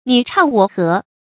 你唱我和 nǐ chàng wǒ hé 成语解释 和：依照别人诗词的题材、体裁和韵脚来写作诗词。